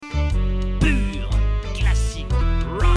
该作品音质清晰、流畅